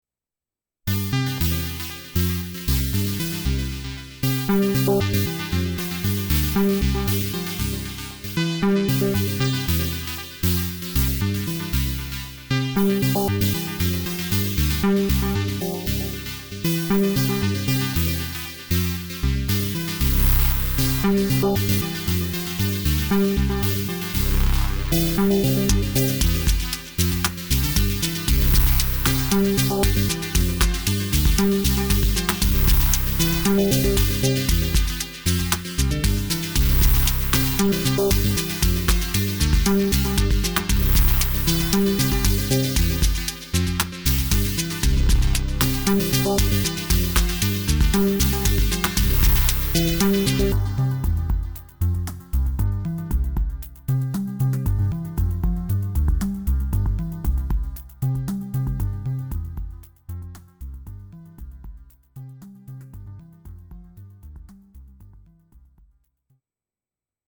Lots of FX track filtering in these.
A dusty sentimental riff @ 116 bpm
I digg the second one… reminds me of some of the sounds in AFX’s Ventolin EP, comfy sounds